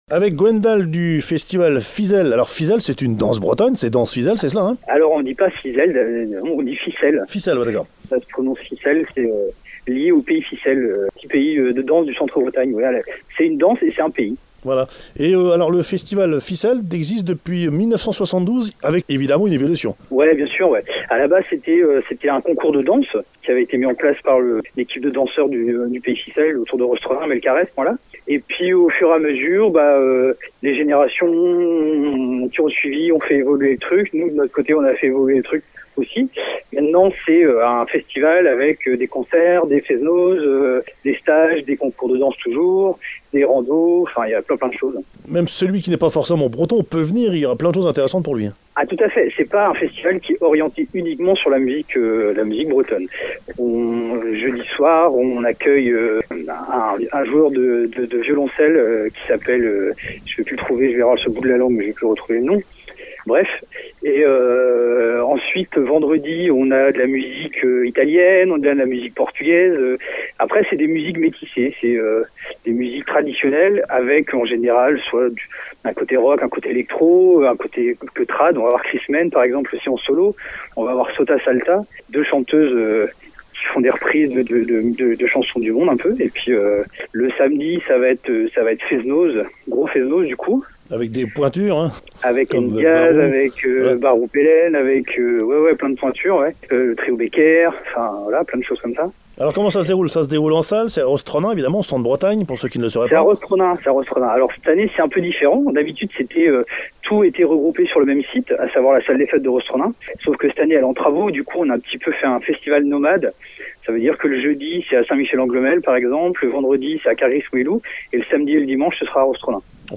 Présentation du festival
au micro de Radio Korrigans et Radio Balises